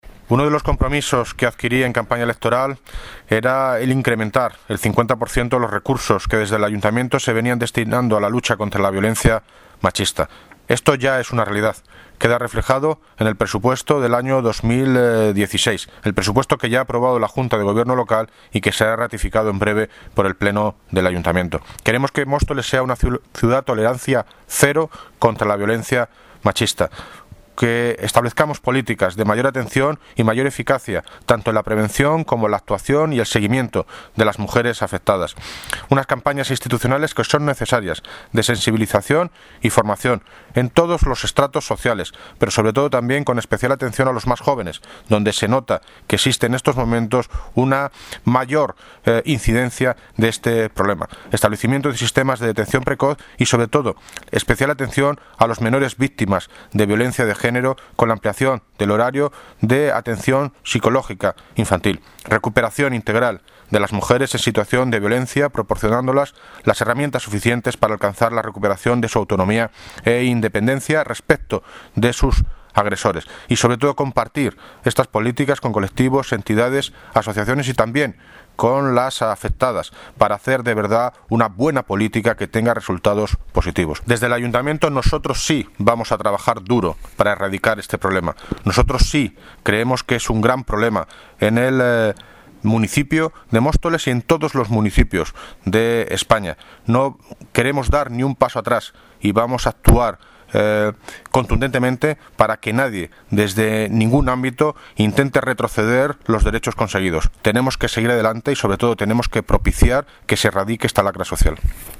Audio - David Lucas (Alalde de Móstoles) Sobre aumento 50% presupuesto lucha violencia de género 2016